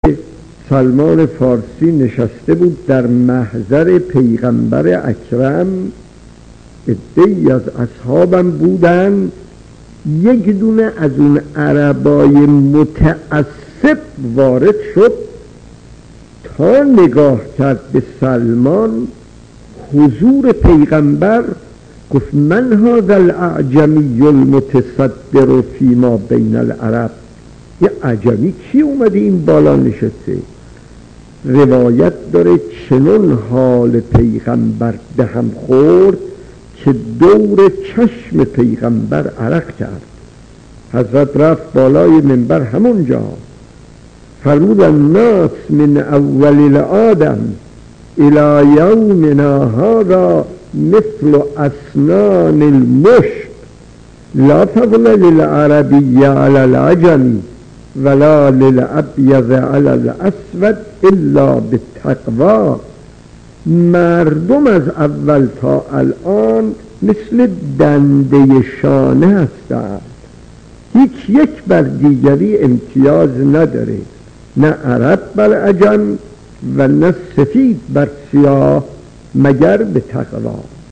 داستان 40 : پیامبر و عرب متعصب خطیب: استاد فلسفی مدت زمان: 00:01:20